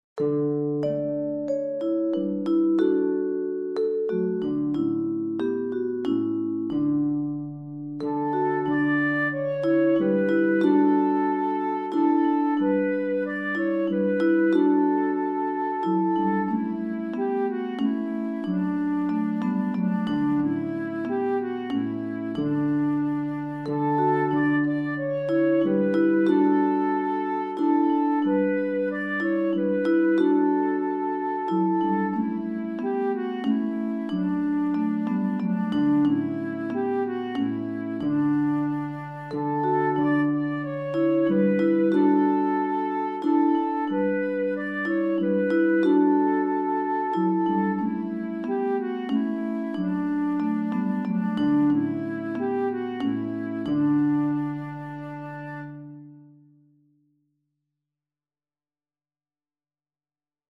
Langsam, feierlich
Langzaam, verheven